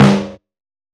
• Fat Snare G Key 553.wav
Royality free acoustic snare sample tuned to the G note. Loudest frequency: 629Hz
fat-snare-g-key-553-sN8.wav